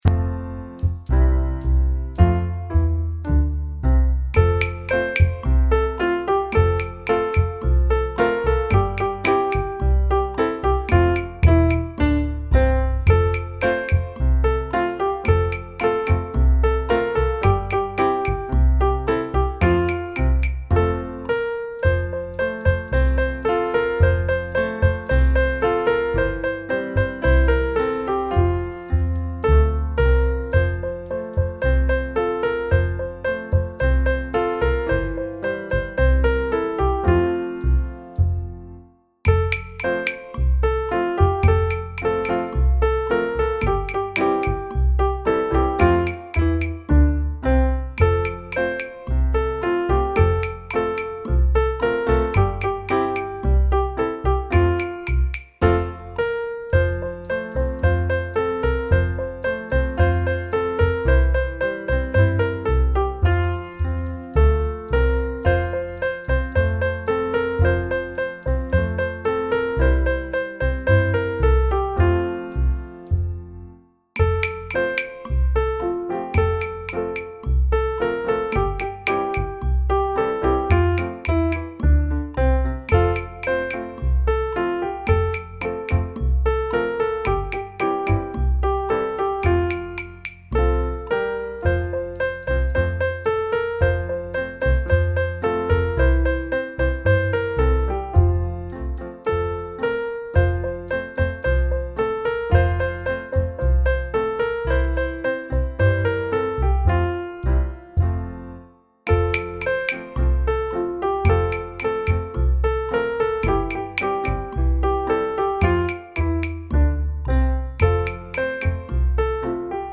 Playbackversion